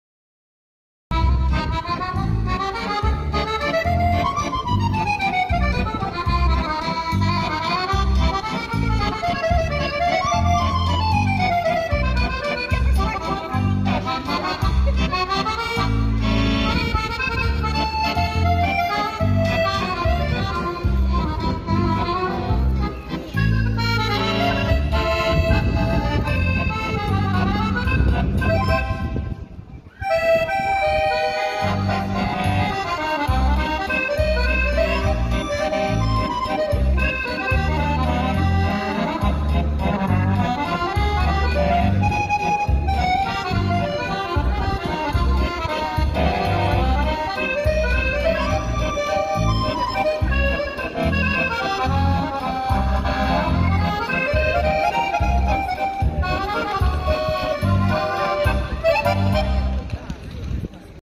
Наипрощание Вальс с Курортного романа от волшебного аккордеониста.
vals-na-proschanie..mp3